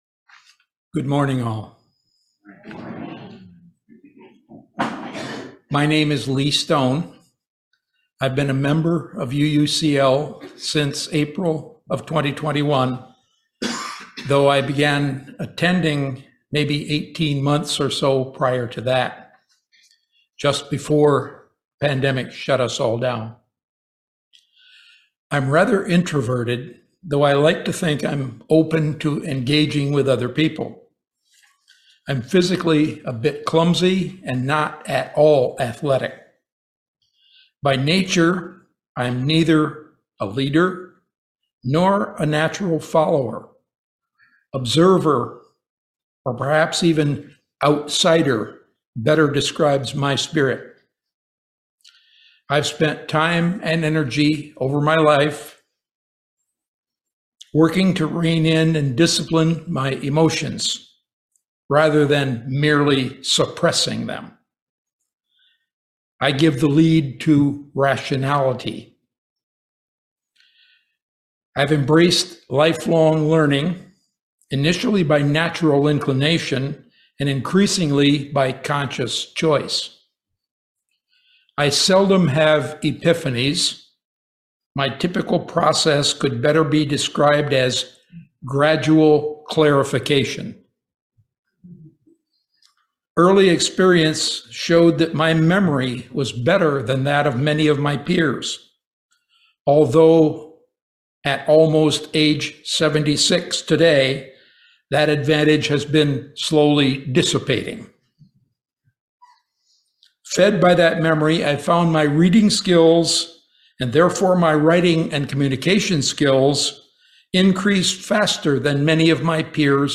In this sermon, three members of a Unitarian Universalist congregation share their personal stories of moving from traditional religious upbringing to a more liberal faith community.